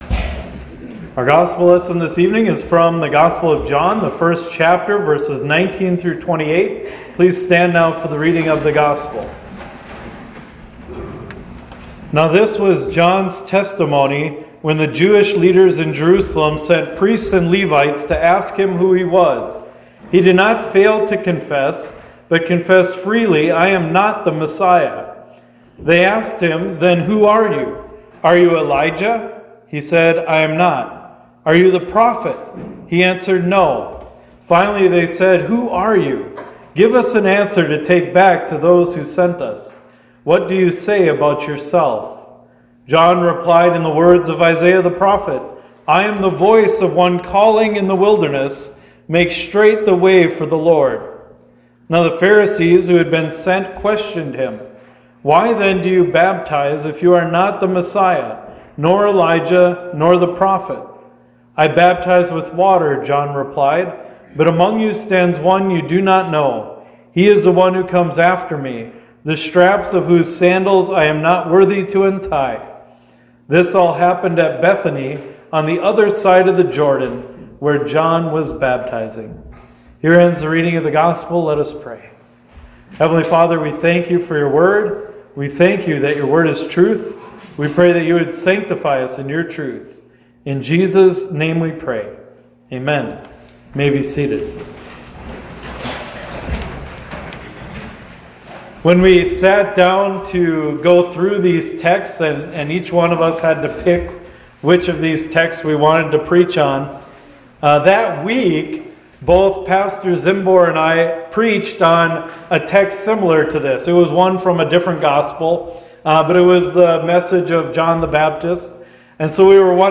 Audio only – Recorded at Hope Lutheran Church in Delmont, SD.